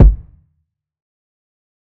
TC2 Kicks10.wav